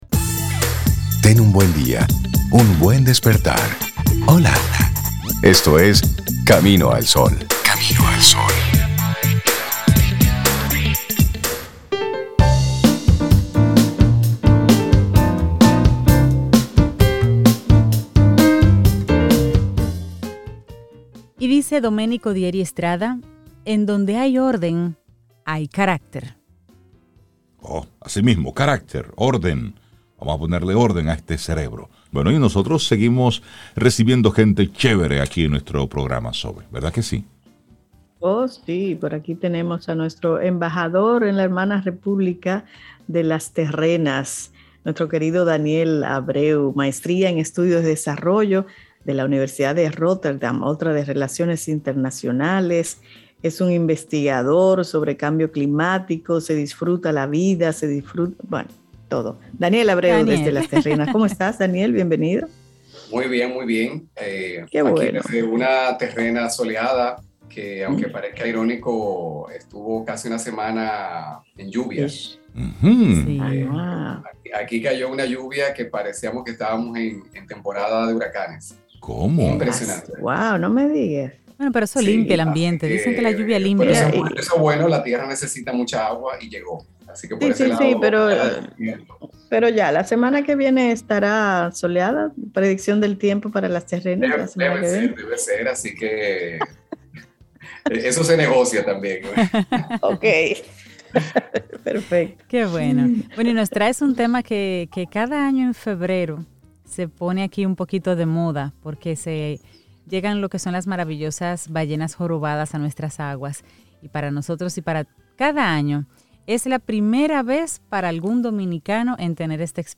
En vivo escuchamos los sonidos de nuestra madre tierra, y de sus verdaderos dueños! En este caso, nos referimos a las ballenas.